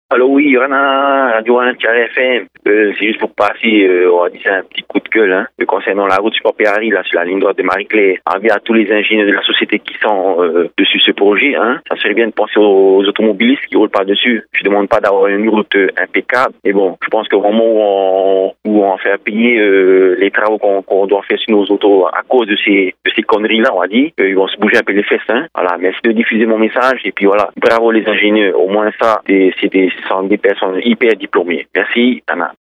Répondeur de 7:30, le 11/03/2021